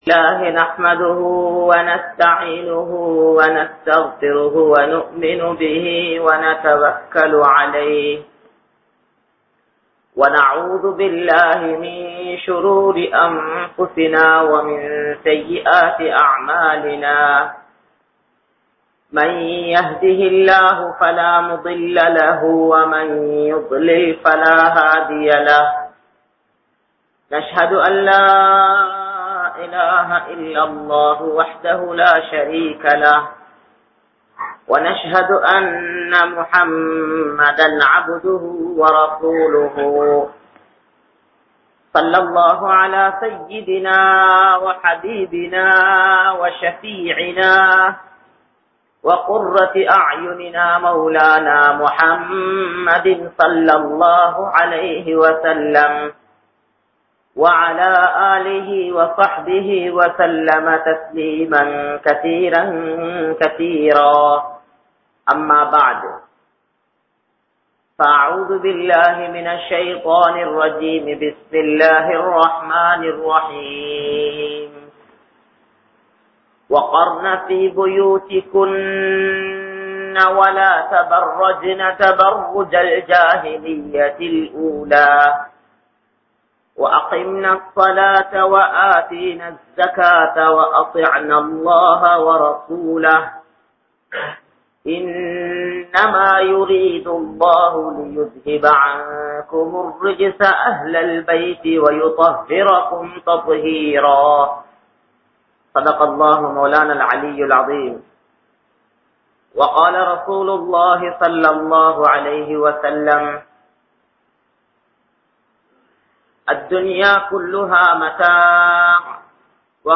Pengalin Vetkaththai Illaamal Aakkufaverhal | Audio Bayans | All Ceylon Muslim Youth Community | Addalaichenai
Al Azhar Jumua Masjith